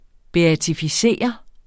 Udtale [ beatifiˈseˀʌ ]